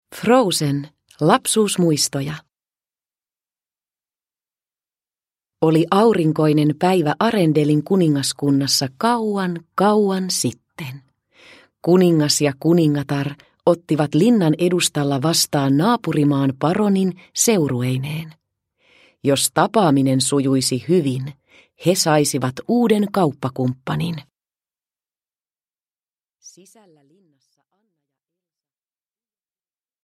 Lapsuusmuistoja – Ljudbok – Laddas ner